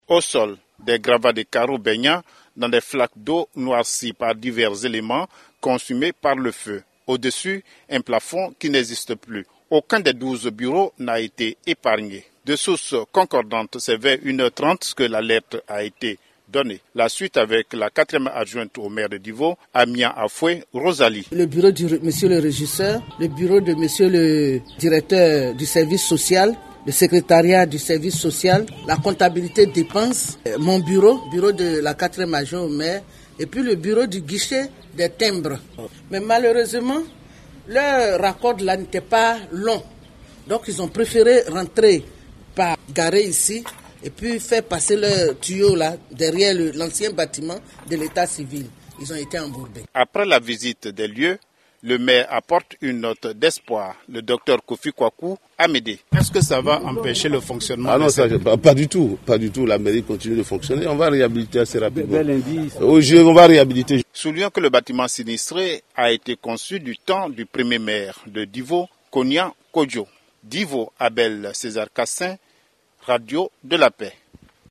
Un violent incendie a ravagé dans la nuit d’hier (du vendredi 8 au samedi 9 juillet) tout le bâtiment abritant les services financiers, de la comptabilité et du service socio culturel de la commune de Divo. Archives, matériels de bureaux et mobiliers ont été entièrement consumés par les flammes en dépit de l’intervention des pompiers. En ligne de Divo